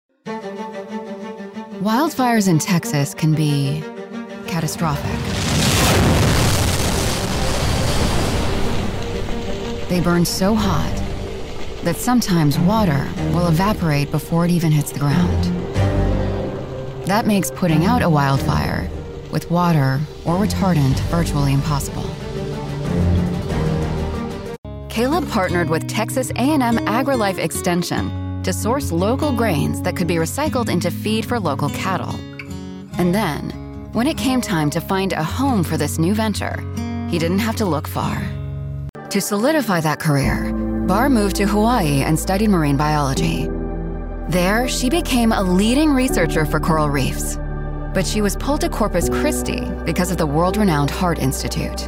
From witty and wry to warm and compassionate, I've got you.
Documentary Narration